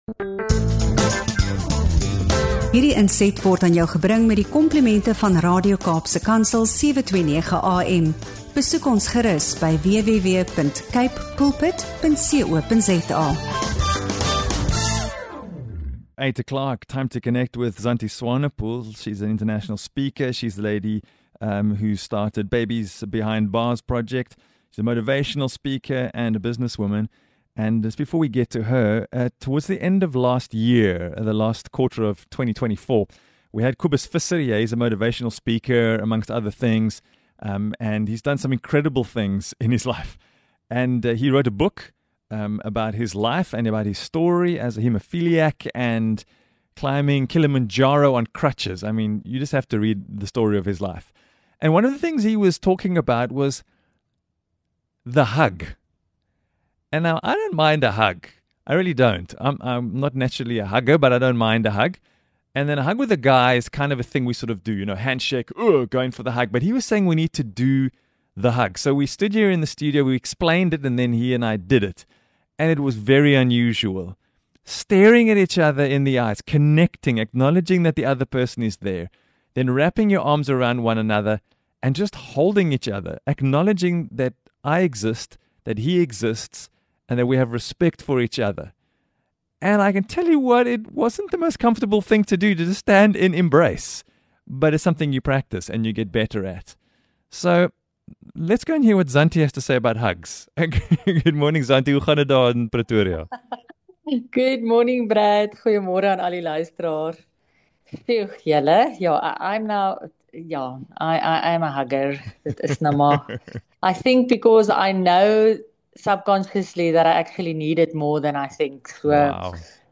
Ons duik in die terapeutiese voordele van 'n drukkie, insluitend stresverligting, die verhoging van oksitosienvlakke en die bevordering van selfbeeld. Leer hoe 'n drukkie 'n verskil in jou fisieke en geestelike gesondheid kan maak, en ontdek die belangrikheid om ander te omhels in hierdie insiggewende gesprek.